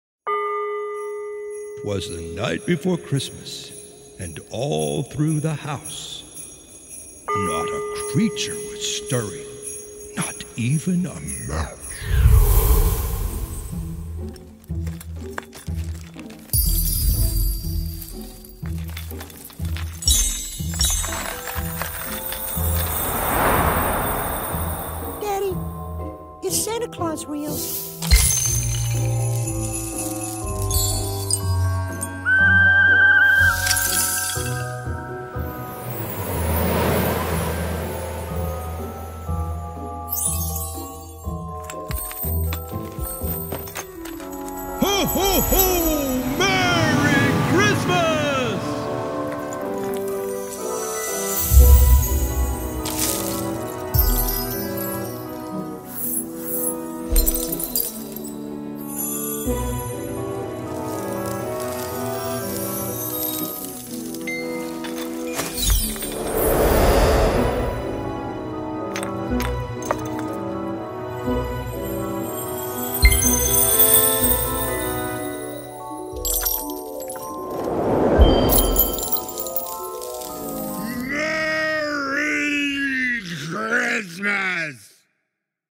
Звуки, которые можно услышать только на праздниках и различных мероприятиях для монтажа видео в mp3.
12. Немного рождества (разные эффекты)